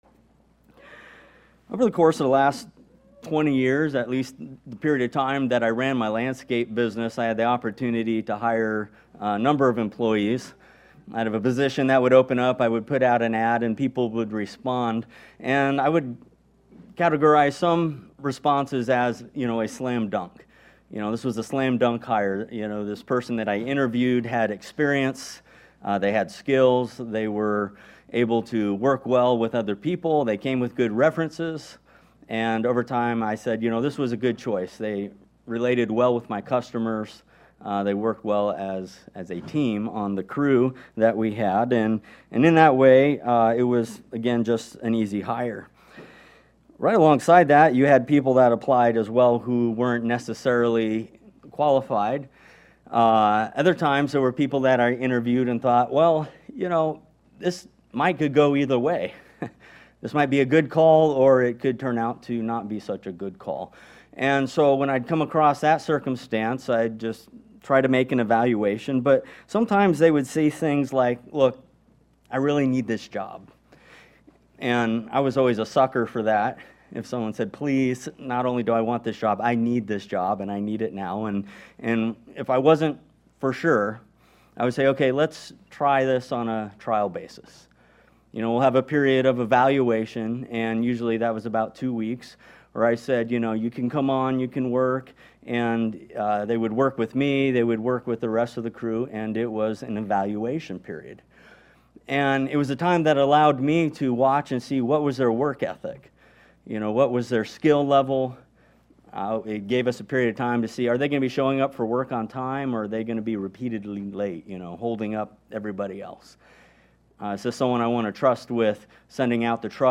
NOTE: The audio level was not consistent; starts on the low side and ends on the high side:-